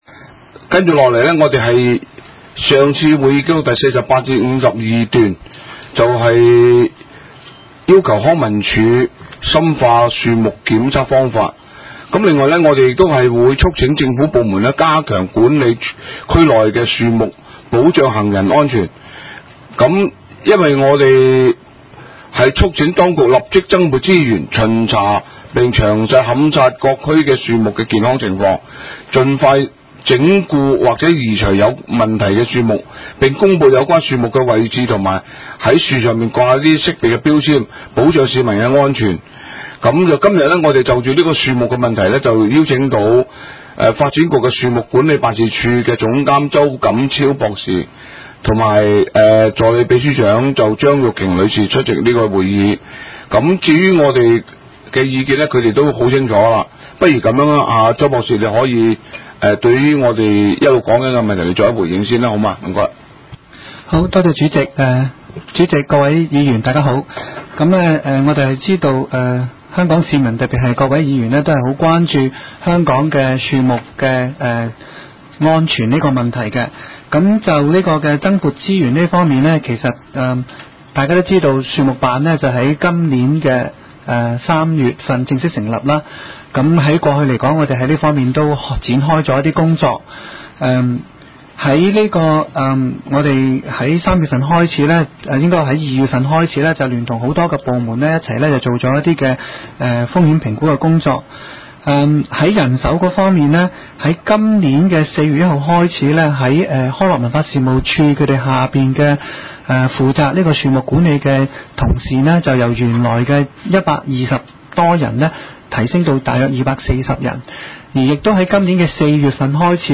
房屋及環境衞生委員會會議